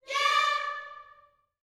YEAH D 5D.wav